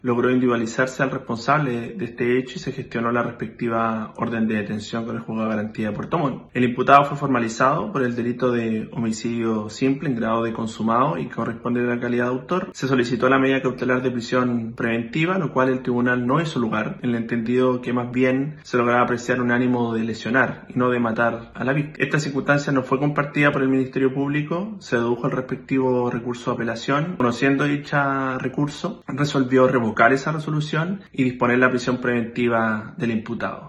Al respecto, el fiscal subrogante de Puerto Montt, Juan Llancas, indicó que debieron apelar para lograr su prisión preventiva.